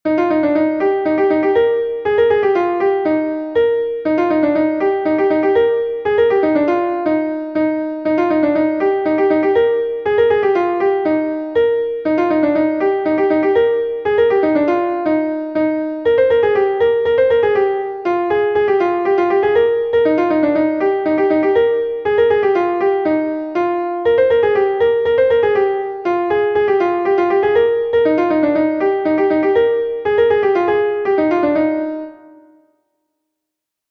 Gavotenn Pleurdud Bro-Bourled is a Gavotte from Brittany